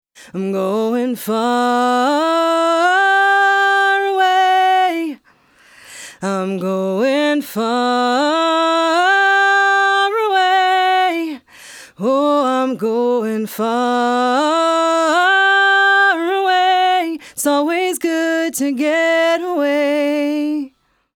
6 db RMS Gain Reduction, 10 db peak
Captured with a Motu 192
Female Vocals - Revive Audio Modified DBX 160x Standard Modification